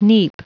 Prononciation du mot neap en anglais (fichier audio)
Prononciation du mot : neap